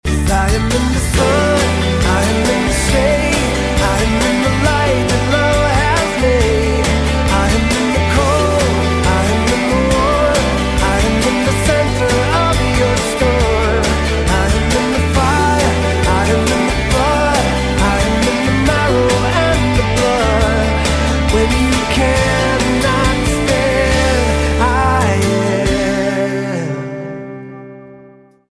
STYLE: Pop
Nice acoustic sounds are heard right from the start of